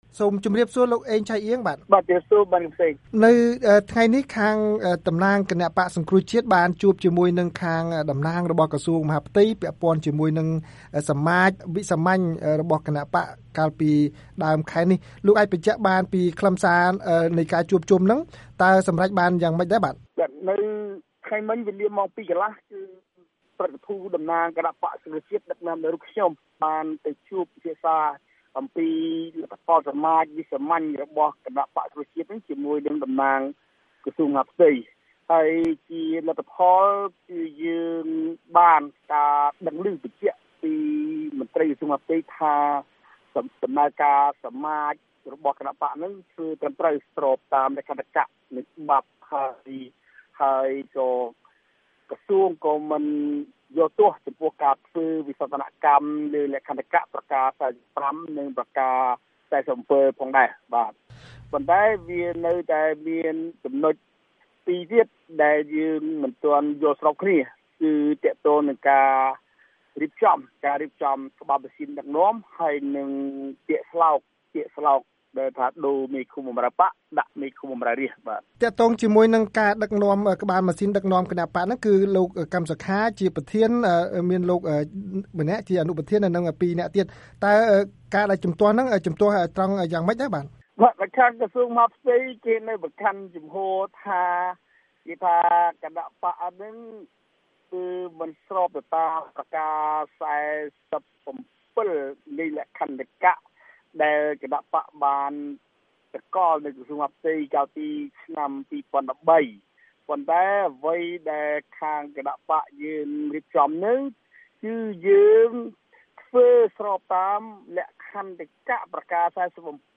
បទសម្ភាសន៍ VOA៖ ក្រសួងមហាផ្ទៃនៅតែមិនទទួលស្គាល់ថ្នាក់ដឹកនាំបក្សសង្គ្រោះជាតិ